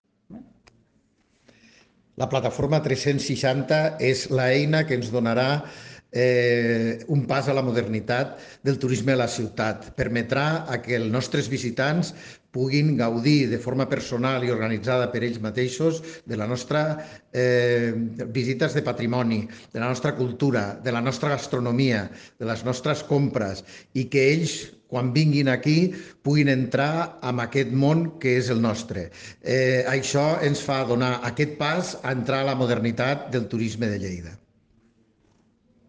tall-de-veu-del-tinent-dalcalde-paco-cerda